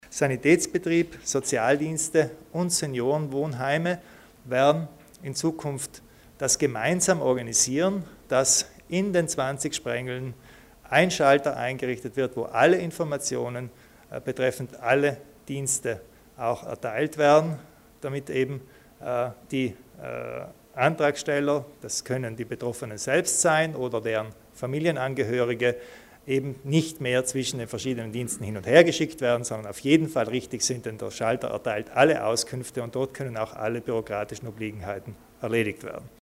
Landeshauptmann Kompatscher erläutert die Neuheiten für Pflege-und Betreuungsangebote